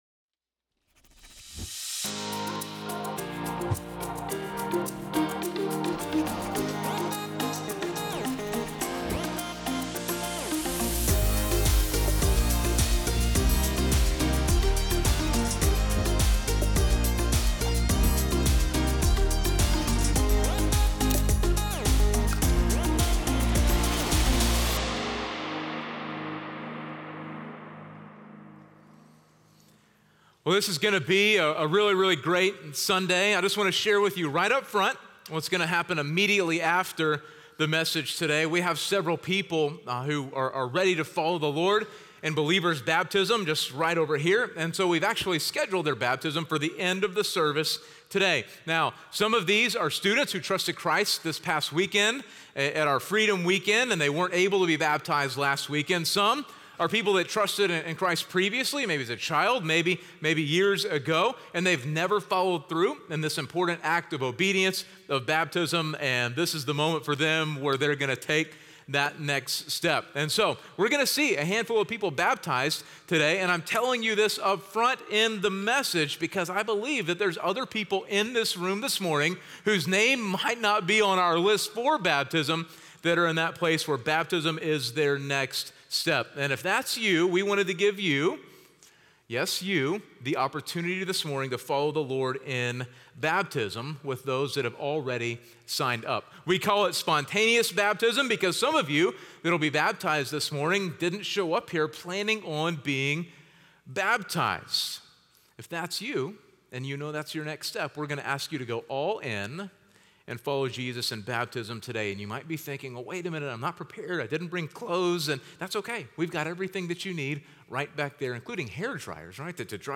North Klein Sermons – Media Player